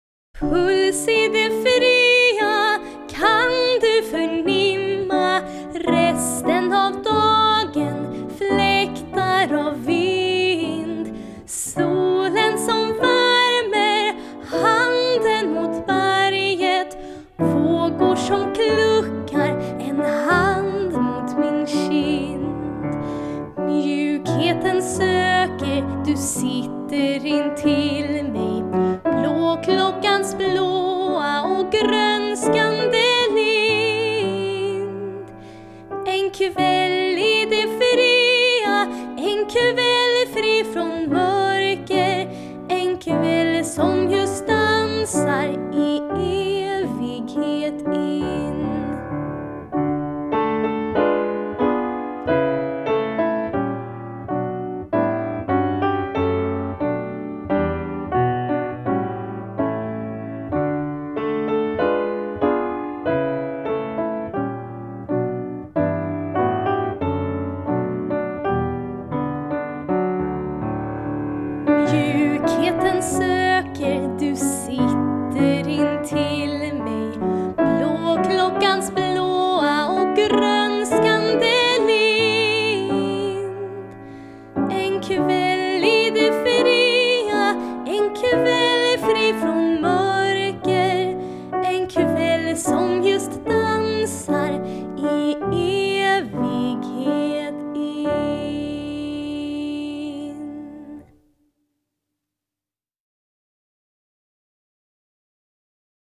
Piano och arr